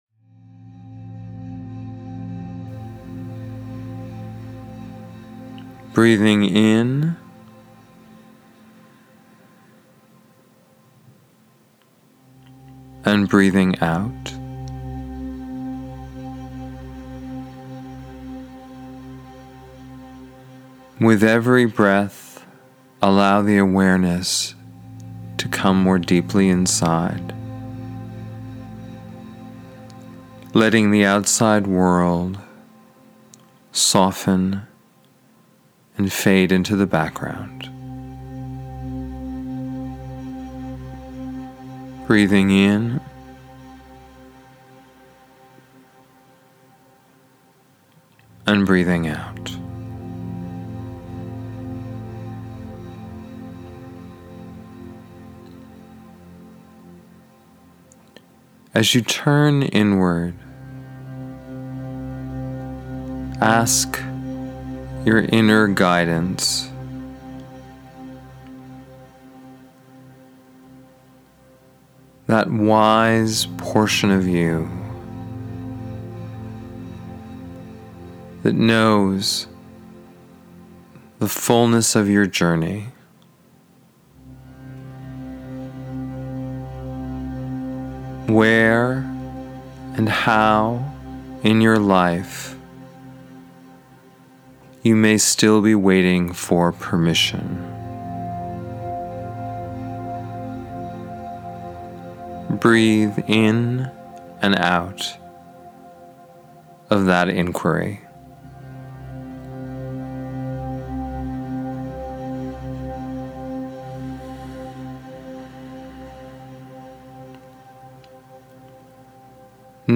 Permission-Meditation.mp3